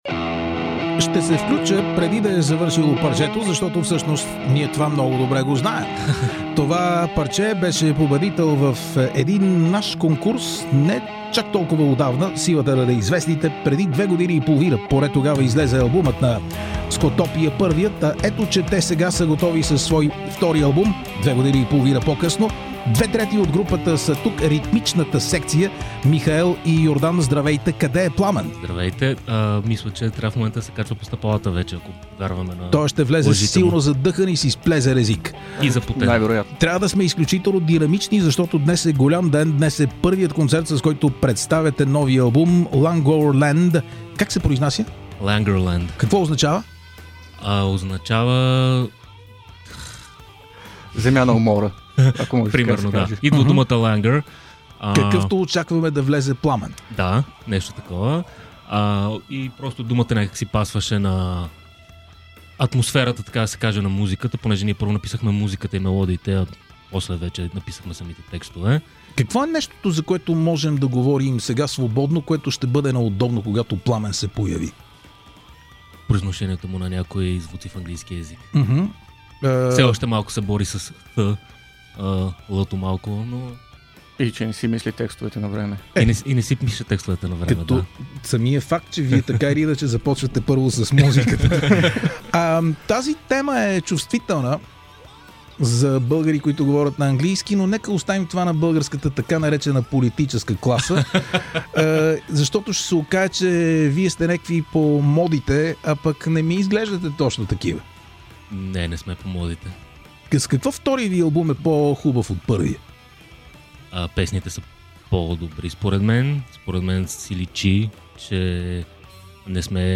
Броени часове преди концерта за представяне на втория си албум ‘Languorland’, и тримата от SCOTOPIA са в студиото на радио ТАНГРА МЕГА РОК.
интервю